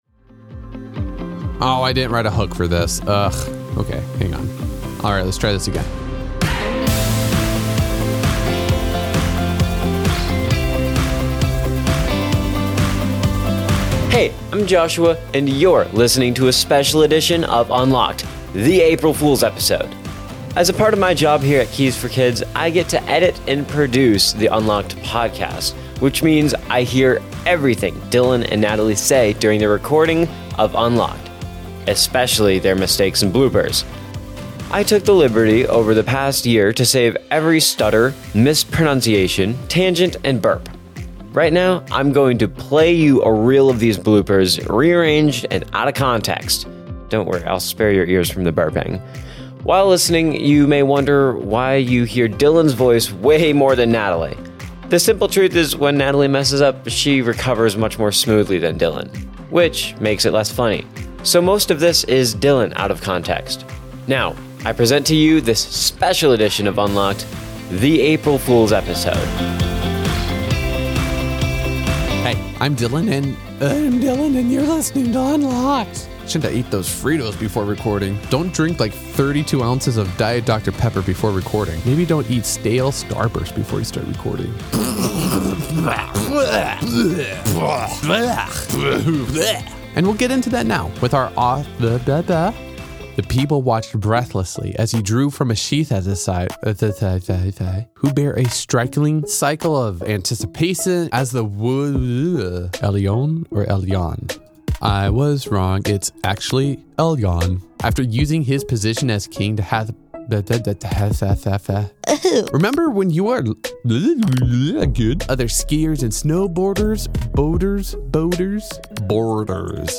It’s unfiltered. It’s unpredictable. It’s Unlocked…and yeah, it’s kind of a mess. But it’s their mess. And we love them for it.